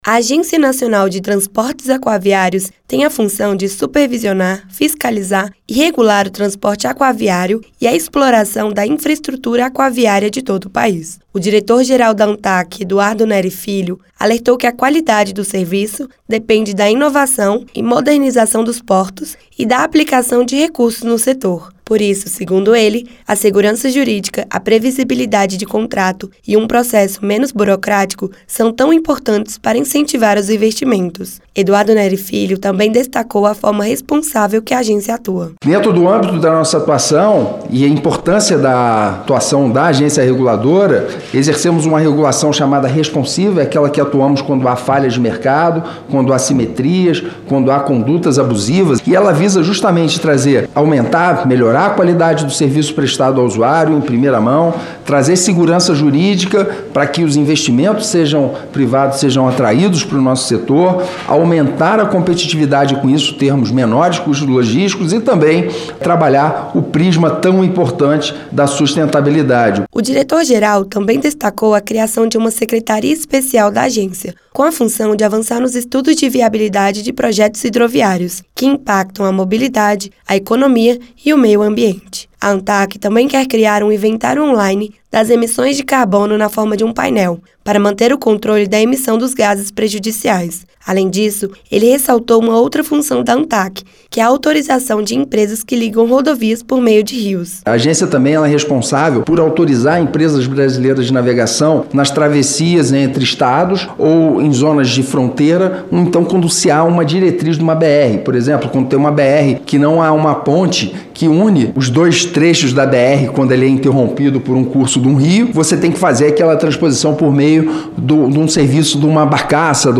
O diretor-geral da Agência Nacional de Transportes Aquaviários (Antaq), Eduardo Nery Machado Filho, disse que a segurança jurídica aumentará os investimentos privados no setor. Ele participou de audiência nesta terça-feira (11) na Comissão de Infraestrutura (CI). Ele apresentou o plano de atuação da agência e comentou o atual cenário da infraestrutura de transportes aquaviários no país.